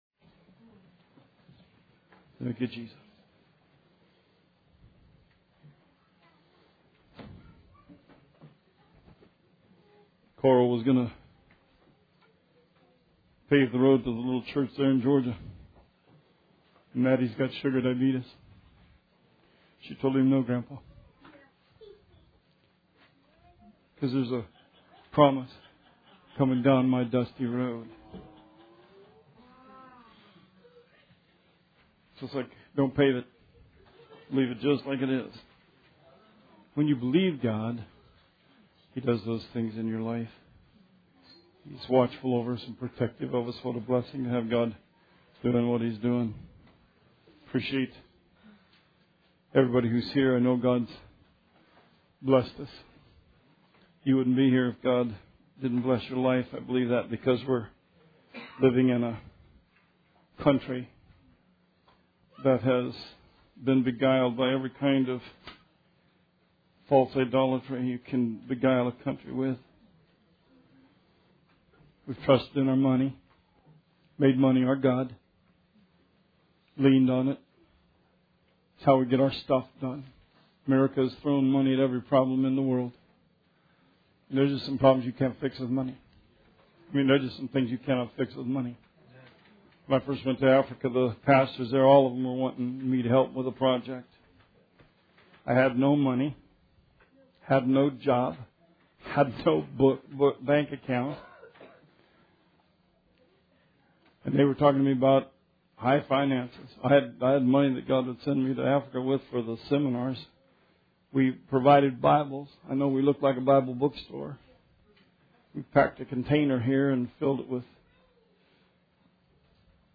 Sermon 9/11/16